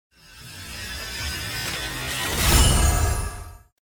open_card_before.mp3